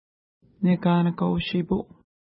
Pronunciation: neka:nəka:w-ʃi:pu:
Pronunciation